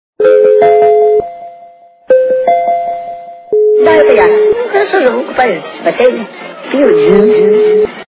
При прослушивании Звонок от Блудного попугая - Да, это я.... качество понижено и присутствуют гудки.